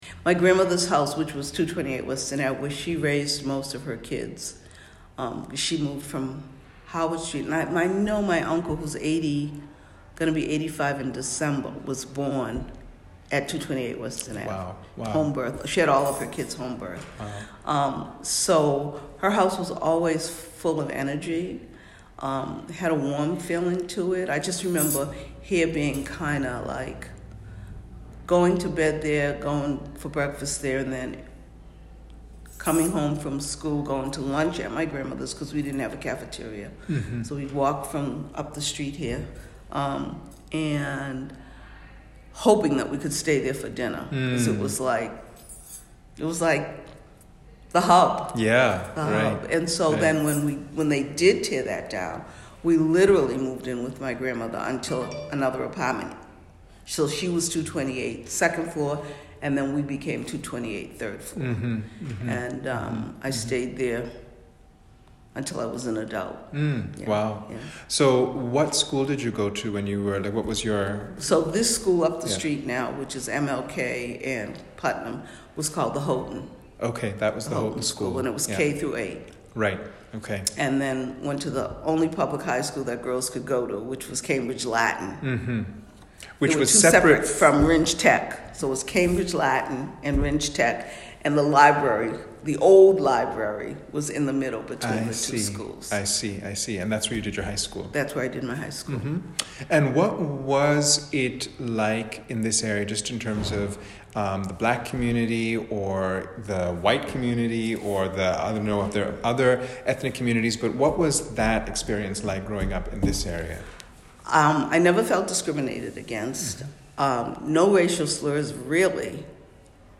Black Cambridgeport Oral Histories
Excerpts of oral histories from members of the St. Augustine's community.